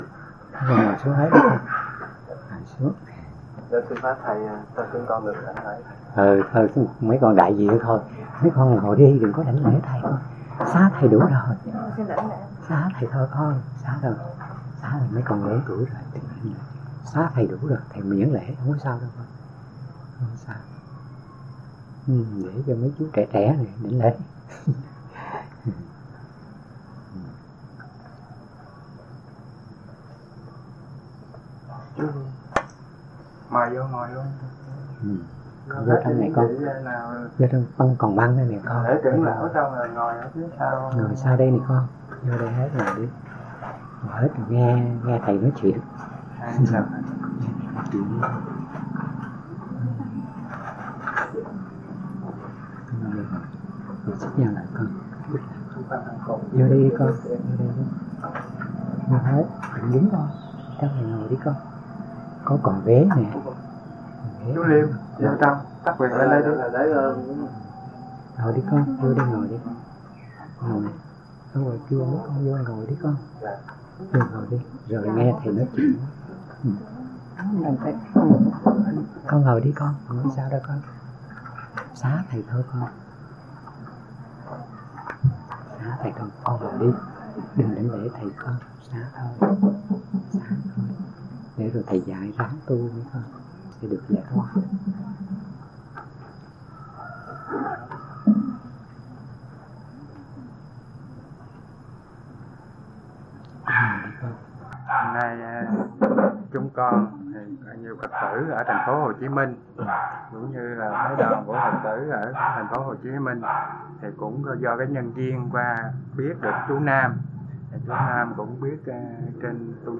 Người nghe: Phật tử